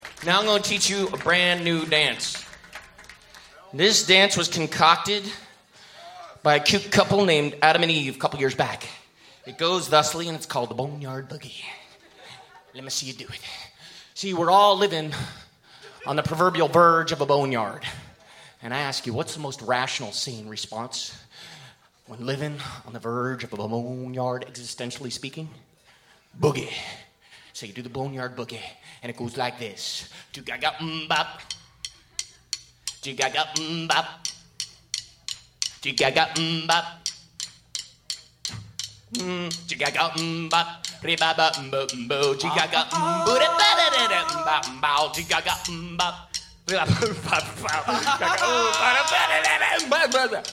UNLEASHED AT ARLENE GROCERY) New York, NY 6-12-02
A jubilant explosion.